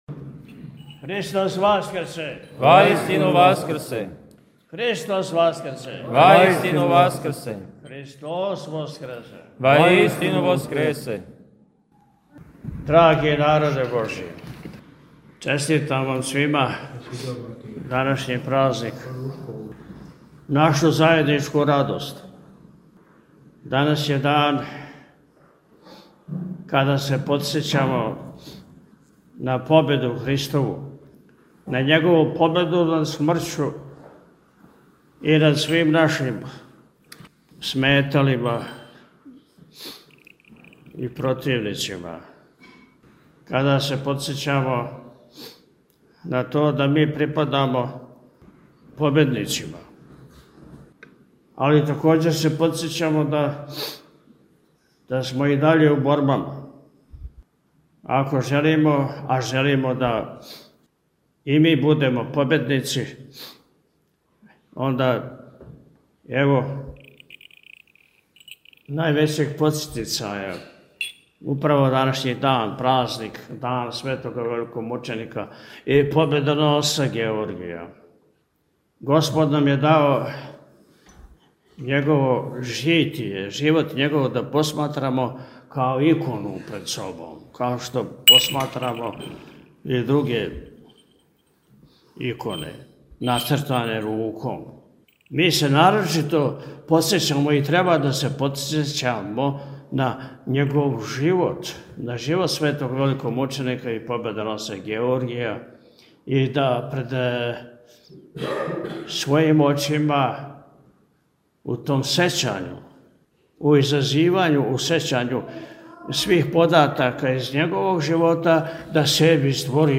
У уторак 6. маја 2025. године Његово Високопреосвештенство Архиепископ и Митрополит милешевски г. Атанасије прославио је у манастиру Милешеви своју Крсну славу Светог великомученика Георгија [...]